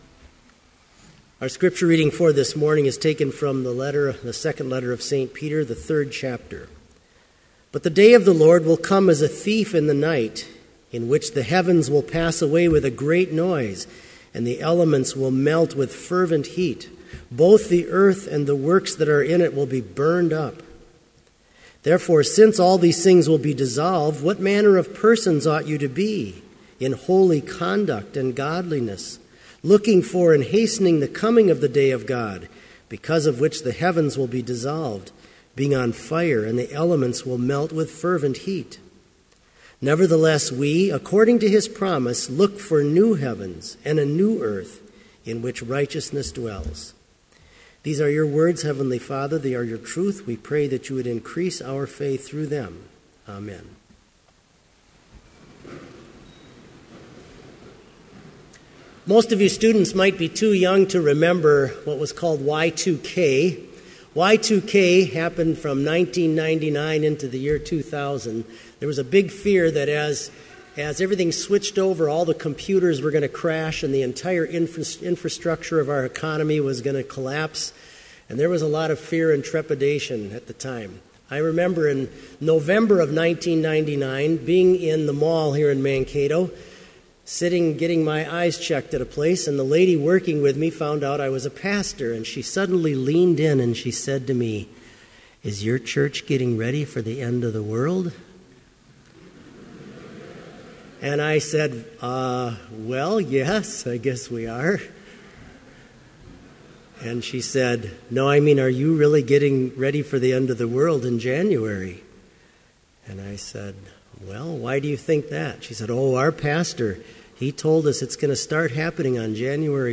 Complete Service
• Prelude
• Homily
• Postlude
This Chapel Service was held in Trinity Chapel at Bethany Lutheran College on Monday, November 10, 2014, at 10 a.m. Page and hymn numbers are from the Evangelical Lutheran Hymnary.